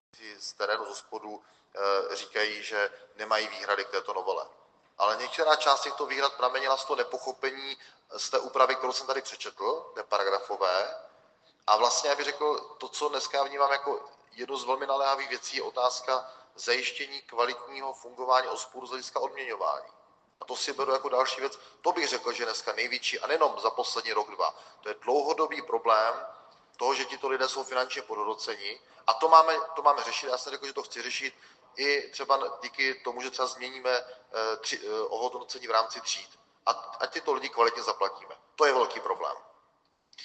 Níže naleznete přepis prohlášení ministra práce a sociálních věcí ze dne 13. prosince 2023 na 86. schůzi poslanecké sněmovny ČR, kde řekl: